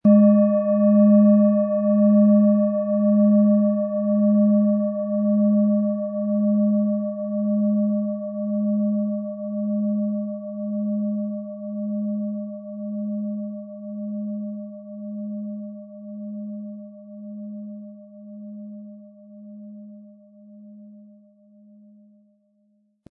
Planetenton
HerstellungIn Handarbeit getrieben
MaterialBronze